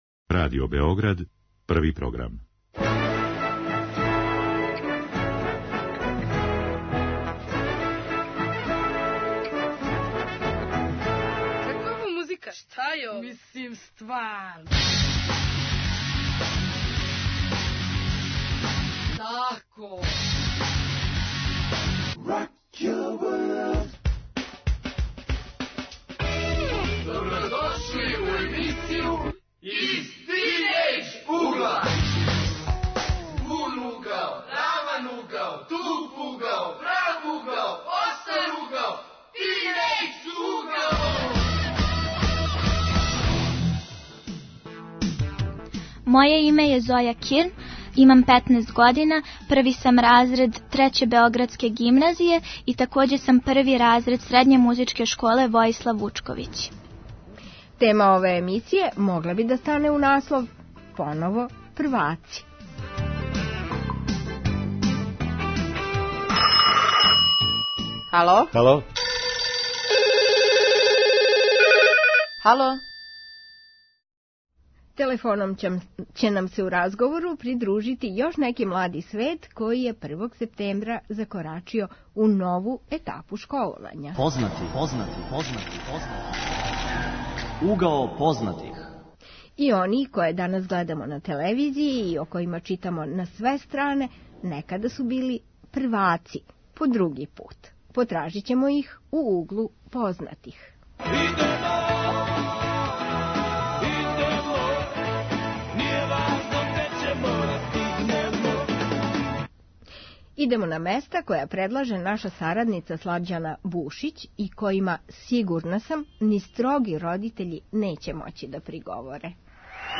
Тинејџери, новопечени ученици средње школе, ћаскају о првим утисцима прикупљеним у новим разредима, међу новим друштвом и професорима.